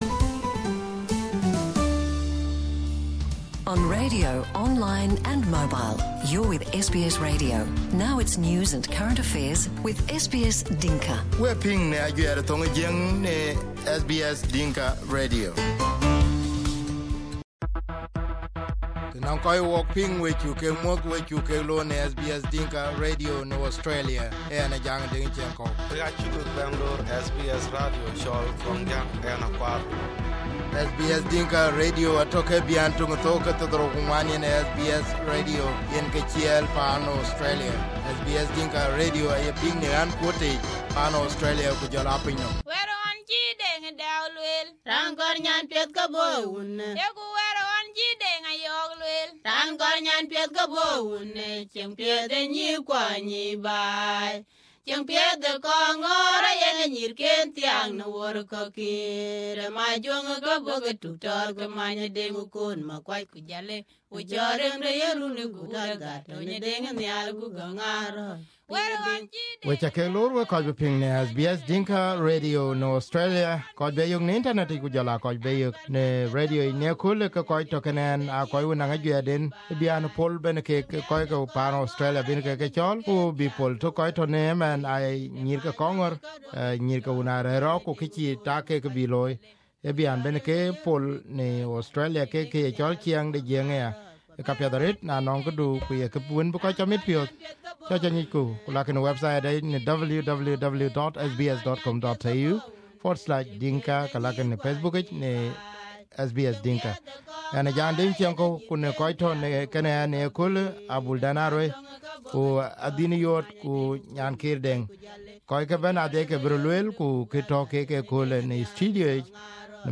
Three of the participants came to SBS Dinka Radio.